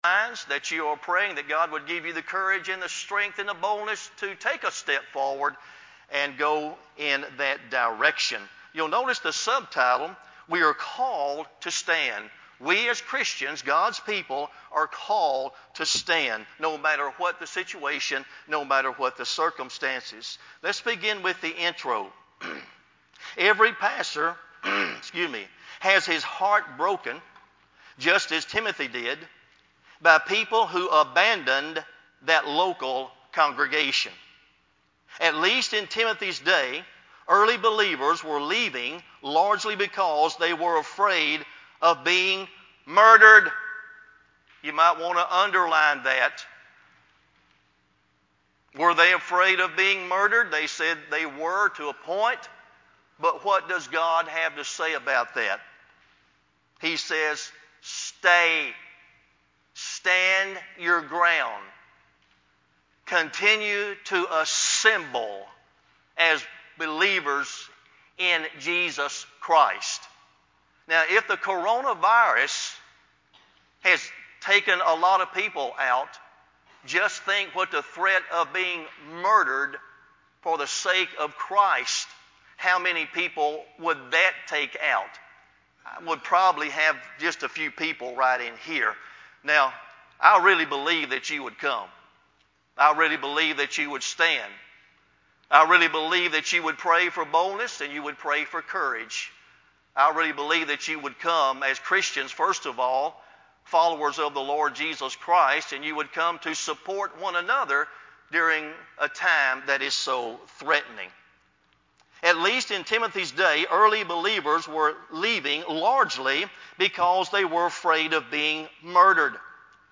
The Front Lines of Battle, We are Called to Stand (Overcoming the Spirit of Fear)…(opening prayer missed, technical slip)
sermon-9-13-CD.mp3